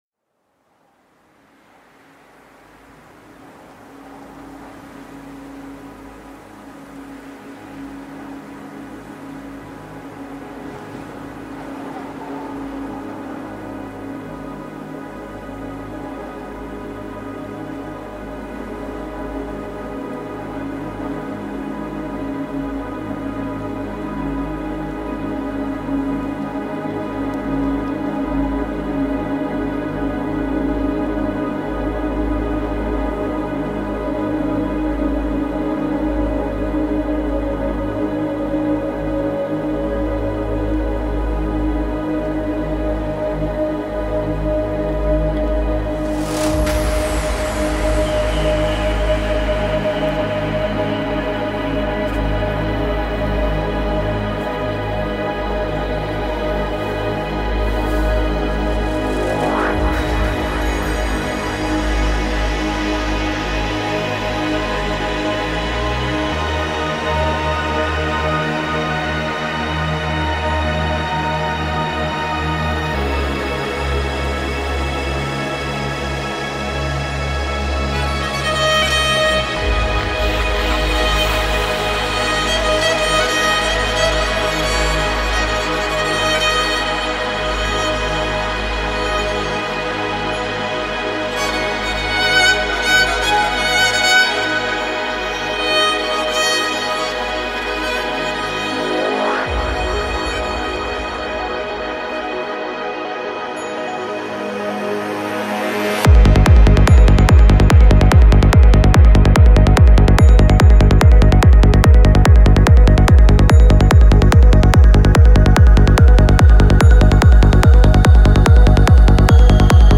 بهترین آهنگ بیس دار خارجی خفن وحشتناک